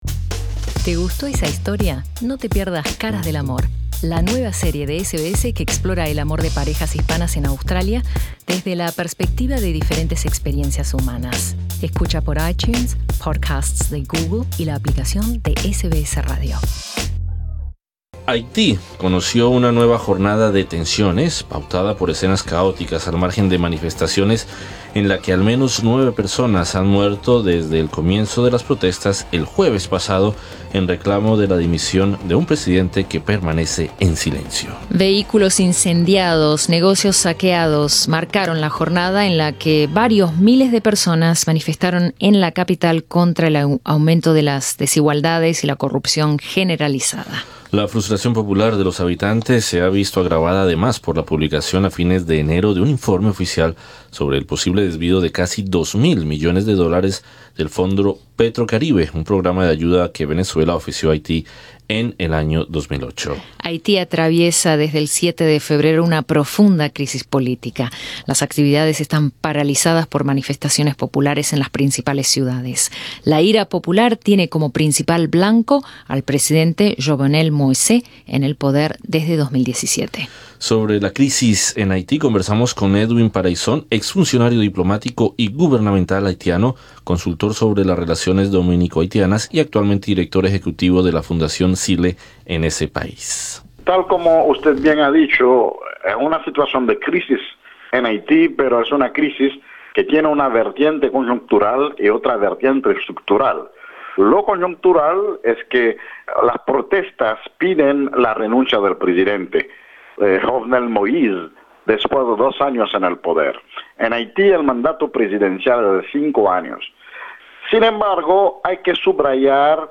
El ex funcionario diplomático y gubernamental haitiano, Edwin Paraison, explica para SBS Spanish, la necesidad de transformar el estado y refundar el país. Escucha la entrevista.